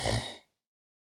HippoSnores-009.wav